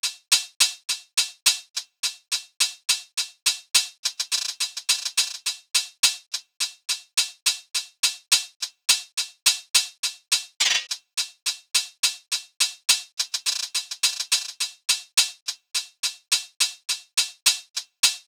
Wake Up Hihat Loop (105BPM).wav